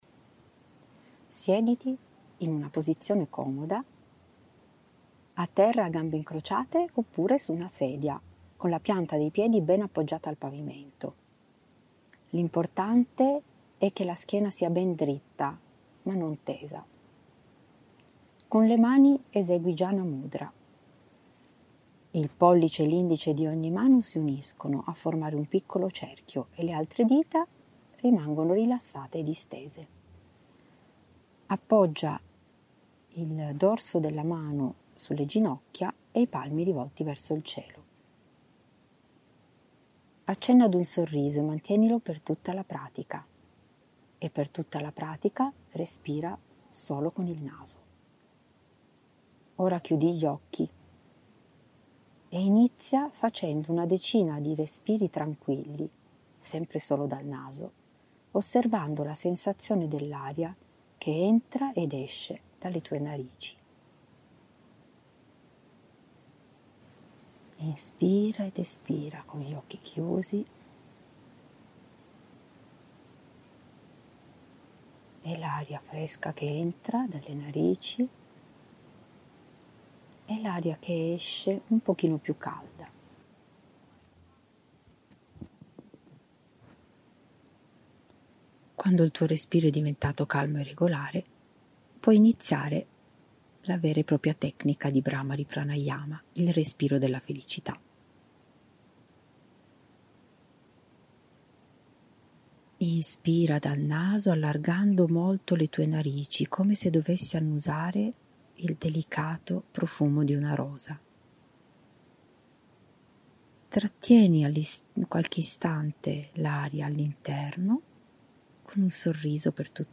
3) prima di sentire qualsiasi fastidio quando il corpo lo richiede comincia ad ESPIRARE (espelli l’aria) LENTAMENTE dal naso, CON LA BOCCA CHIUSA EMETTI IL SUONO Mmmmm, PRODUCENDO UN RUMORE SIMILE A UN’APE IN VOLO (da qui il nome dato alla pratica, il respiro dell’ape).
Sarà quindi un’ESPIRAZIONE SONORA e NASALE.